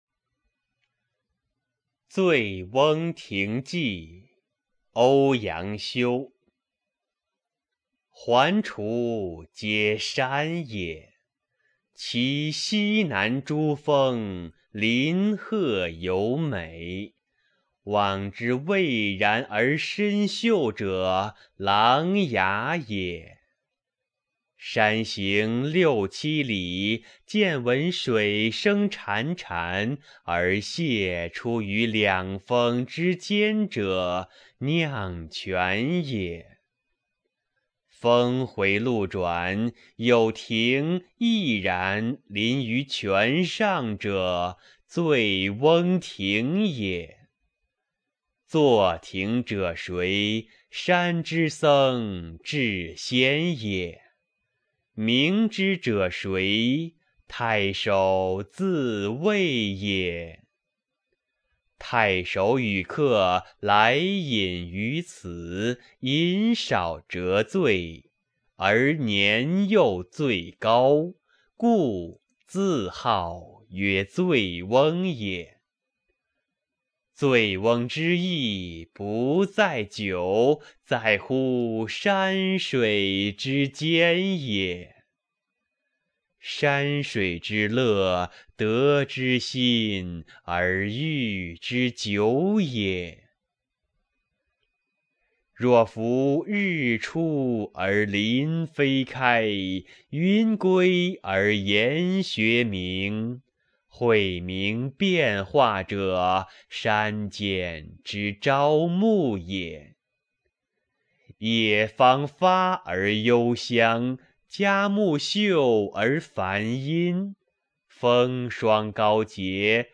《醉翁亭记》朗诵